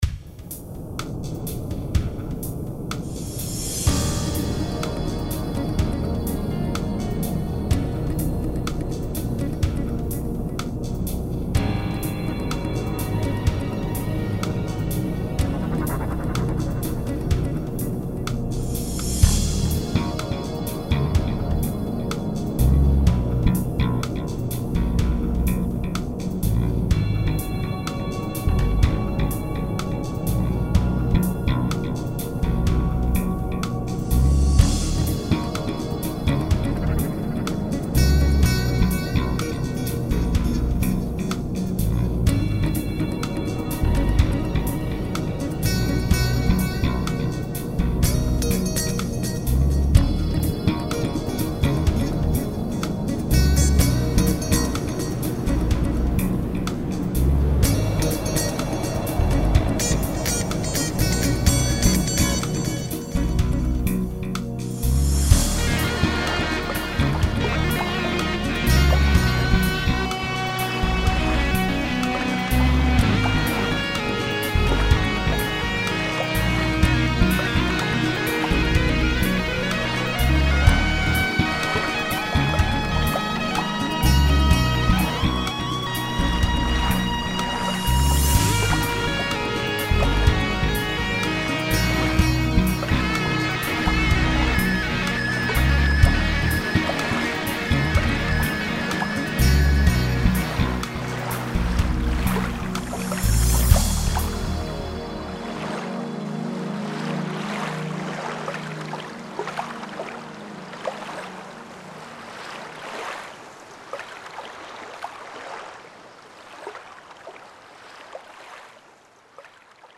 Scene #7 - Slap technique on bass guitar.
Scene #8 - All about tapping and legato techniques.